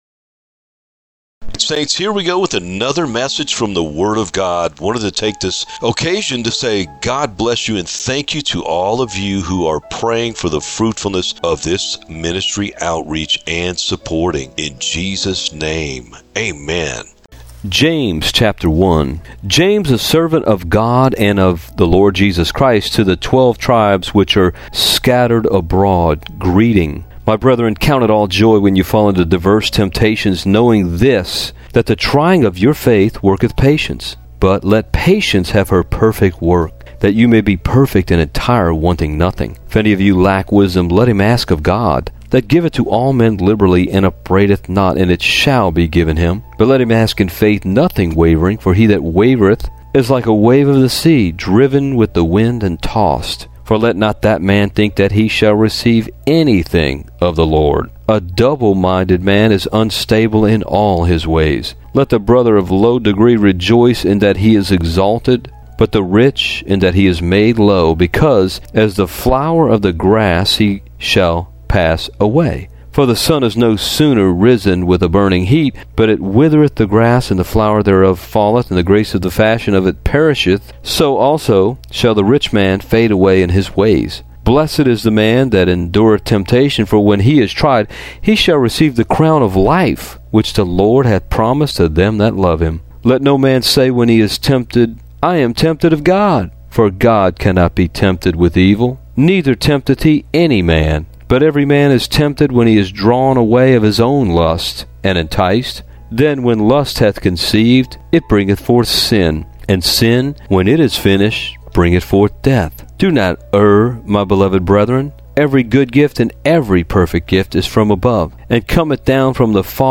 James-whole-book-EDITED-MUSIC1.mp3